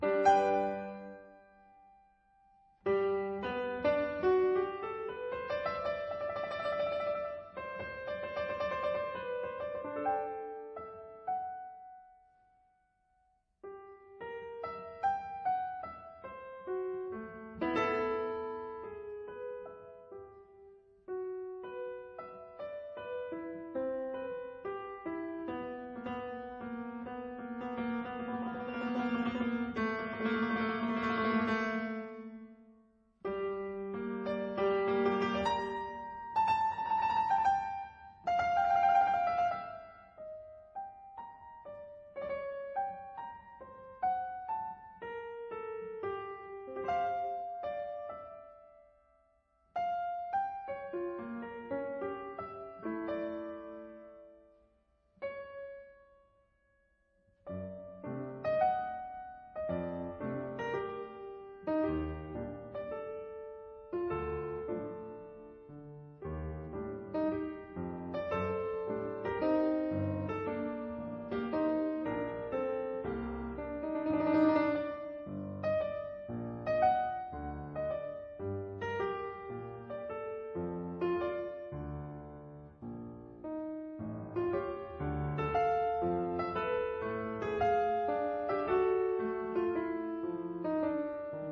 試聽三是1924年寫給鋼琴的奏鳴曲。